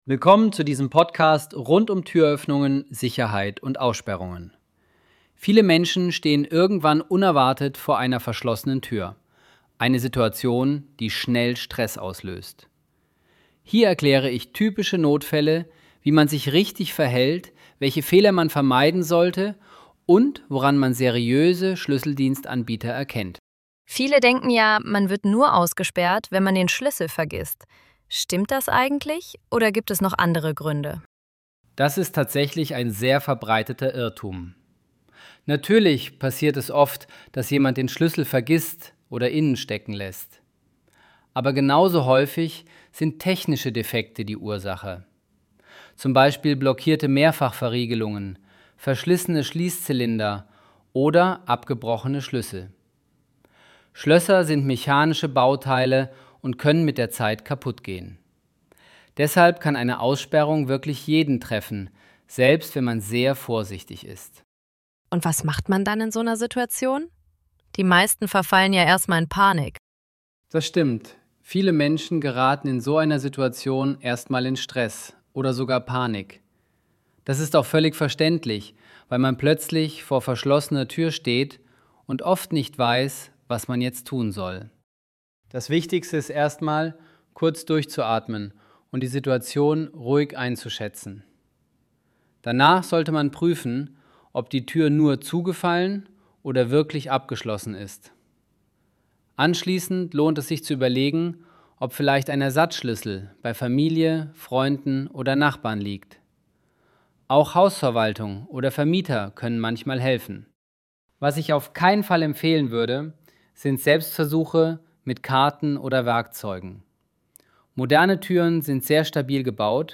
ElevenLabs_Aussperrungen_Technik_Panik_und_die_besten_Loesungen-2.mp3